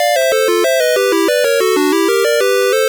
One of the jingles that plays at the start of a level
Source Recorded from the Sharp X1 version.